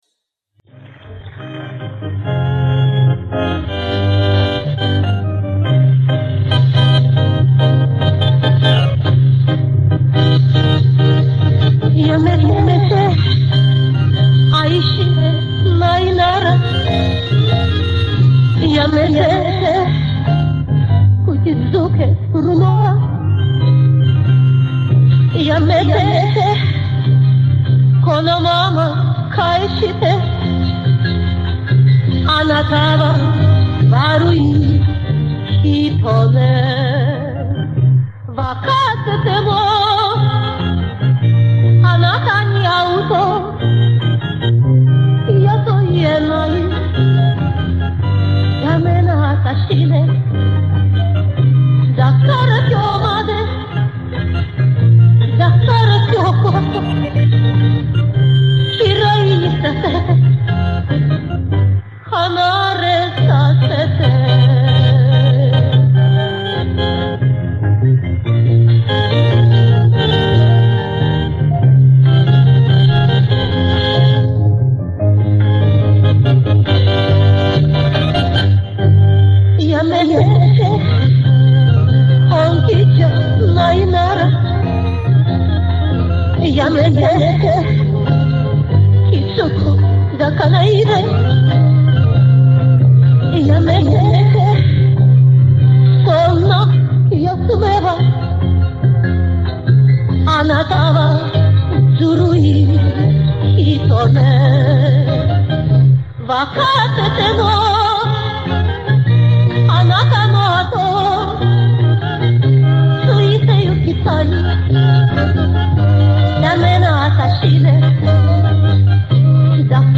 Запись с концерта.